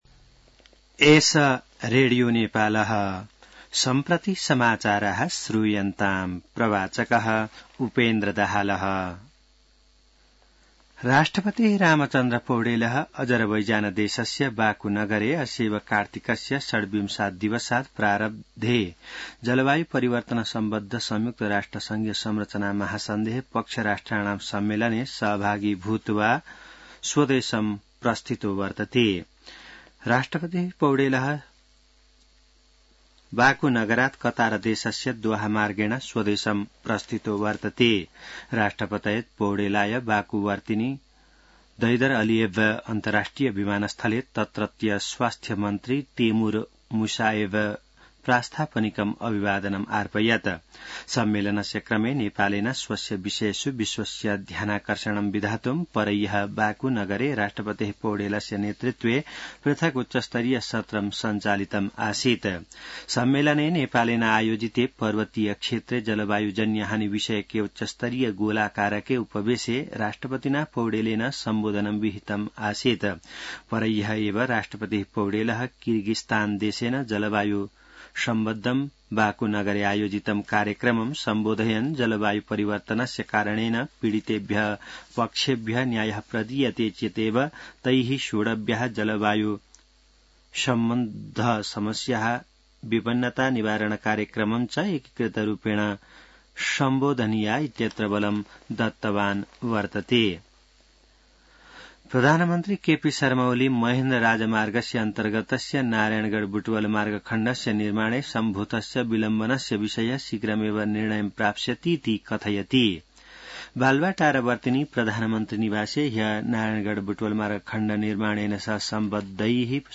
संस्कृत समाचार : १ मंसिर , २०८१